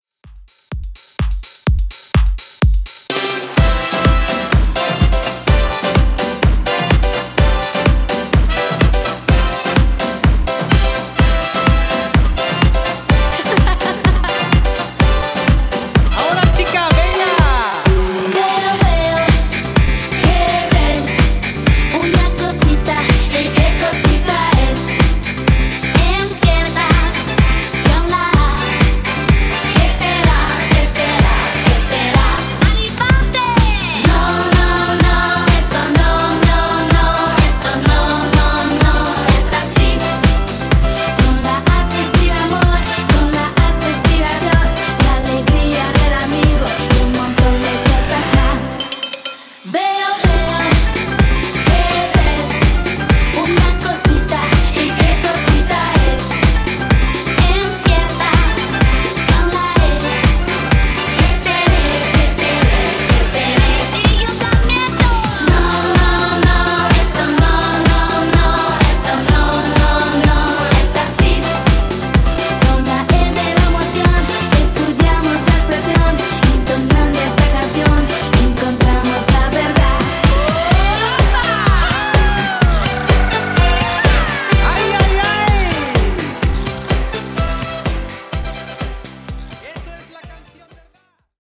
Extended Mix)Date Added